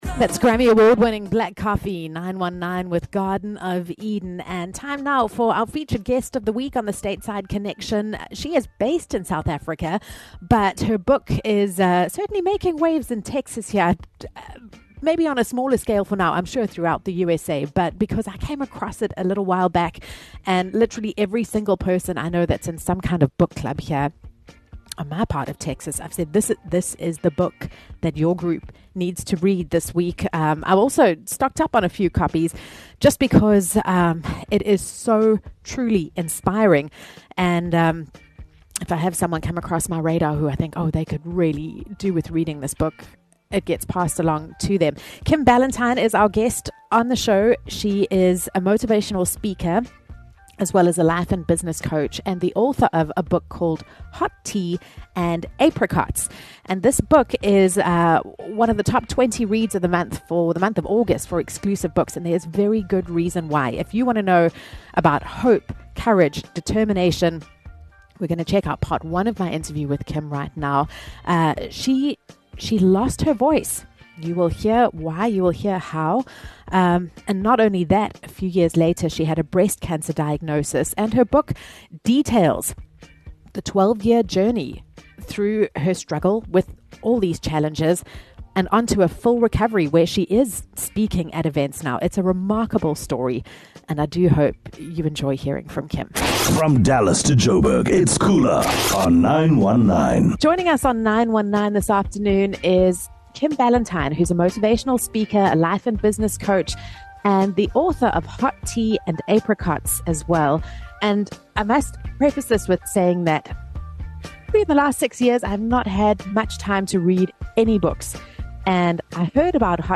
Hear my interview with this incredible woman.